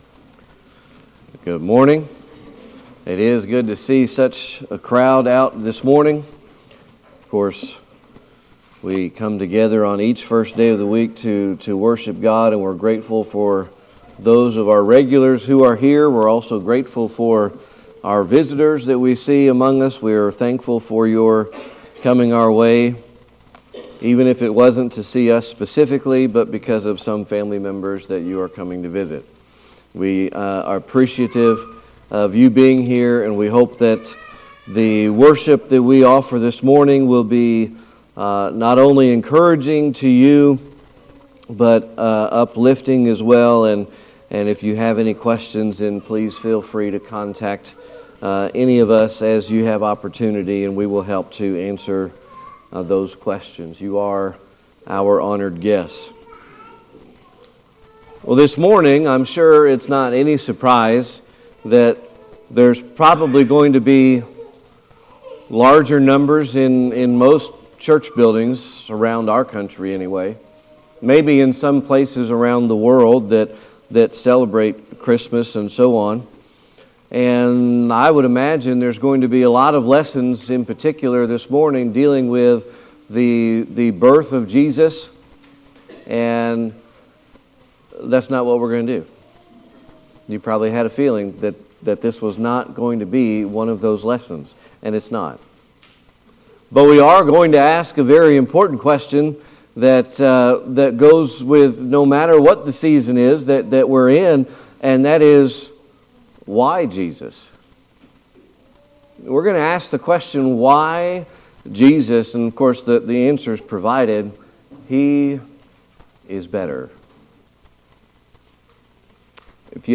Hebrews 1 Service Type: Sunday Morning Worship We are going to ask a very important question